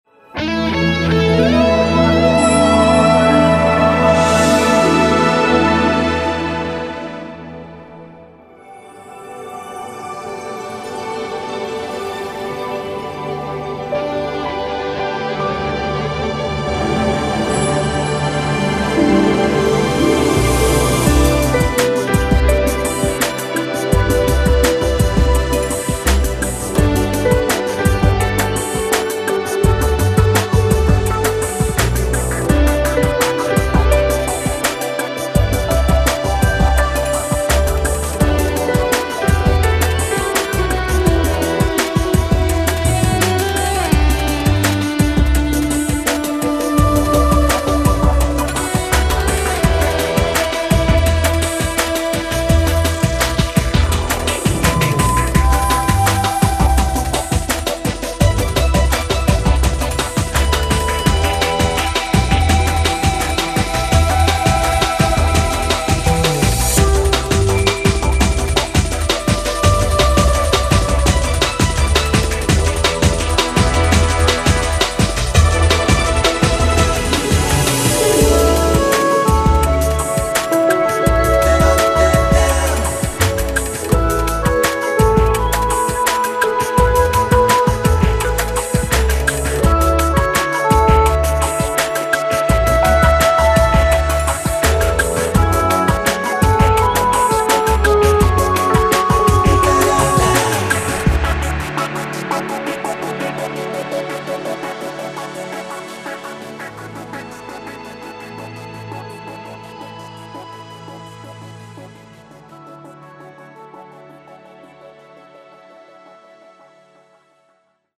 Audiologo/Soundscape